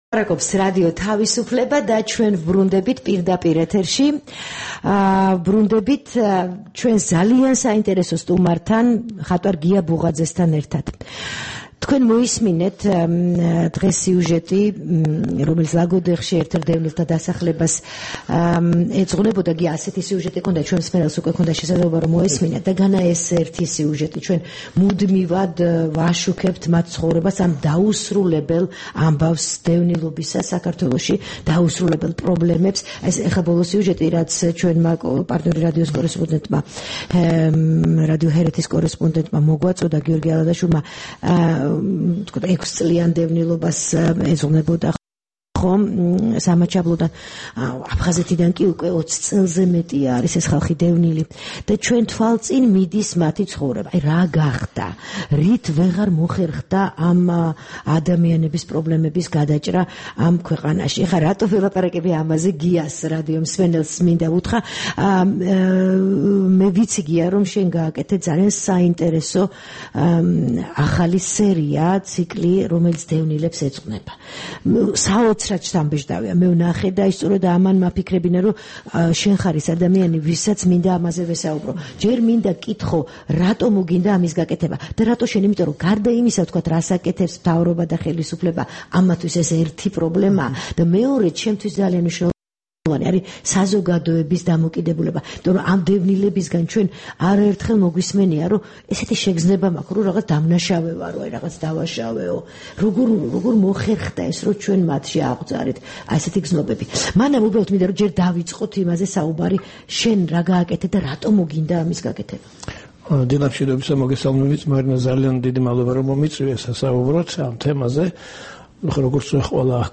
15 აგვისტოს რადიო თავისუფლების დილის გადაცემის სტუმარი იყო მხატვარი გია ბუღაძე, ნამუშევრების ახალი ციკლით დევნილებისა და დევნილობის შესახებ.